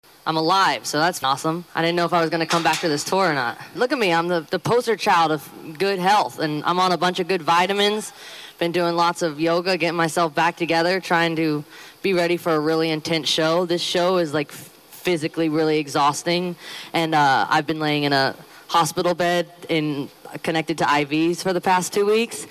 Before the show the singer held an impromptu press conference to let the world know she is back and in good health!